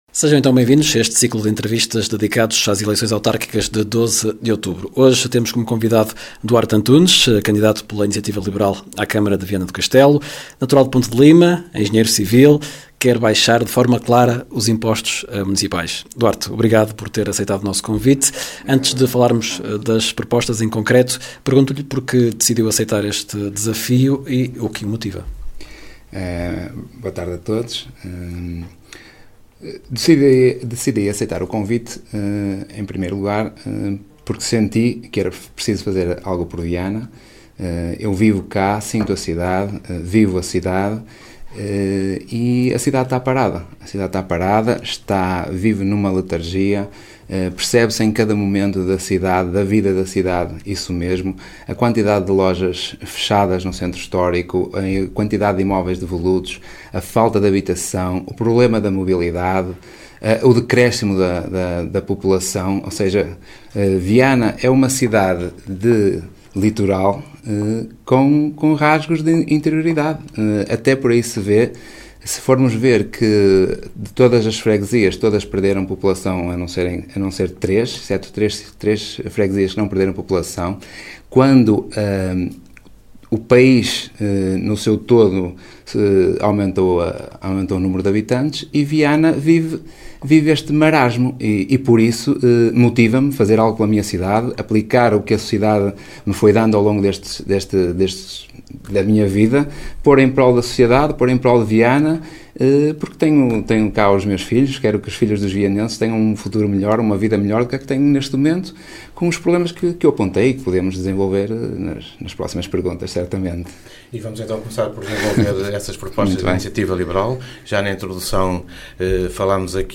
Ação conjunta da Rádio Geice FM e da Rádio Alto Minho, que visa promover um ciclo de entrevistas aos candidatos à presidência da Câmara Municipal de Viana do Castelo.